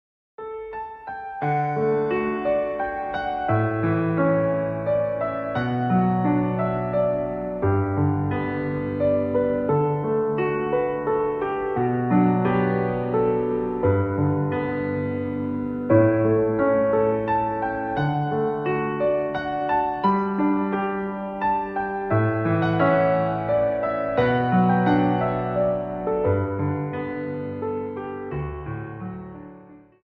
Slow Waltz 28 Song